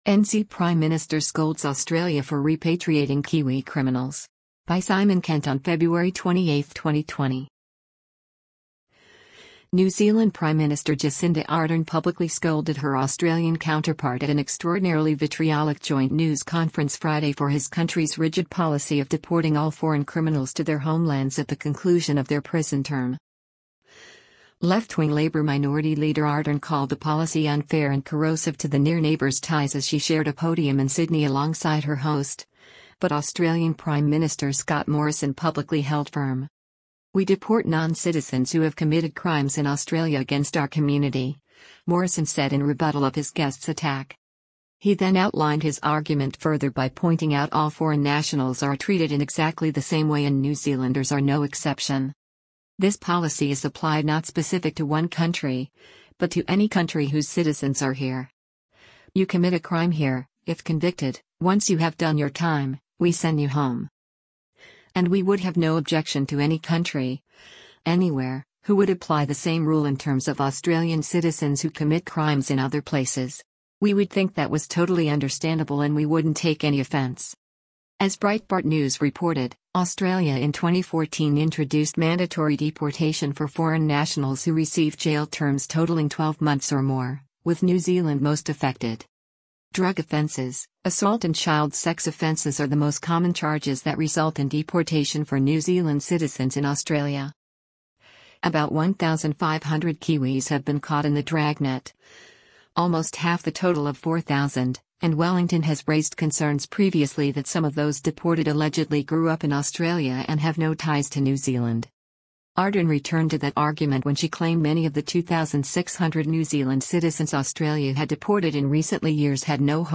New Zealand Prime Minister Jacinda Ardern publicly scolded her Australian counterpart at an extraordinarily vitriolic joint news conference Friday for his country’s rigid policy of deporting all foreign criminals to their homelands at the conclusion of their prison term.